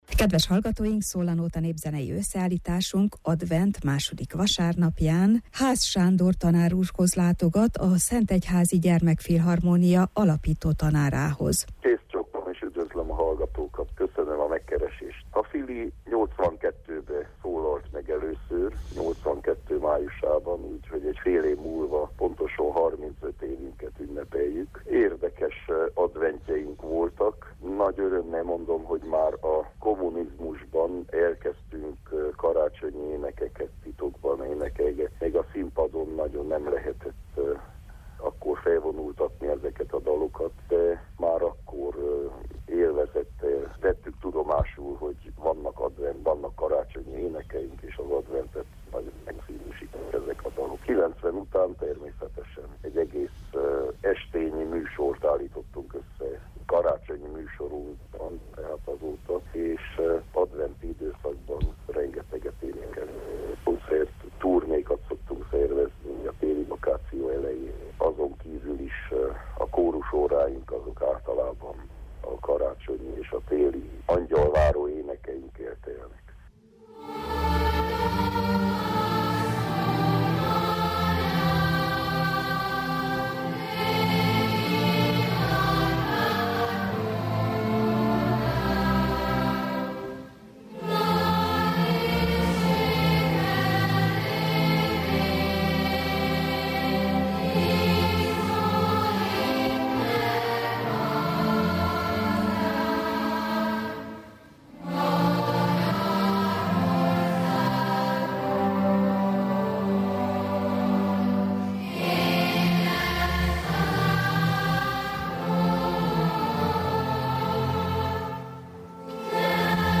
A Szól a nóta rovatunk népzenei összeállításában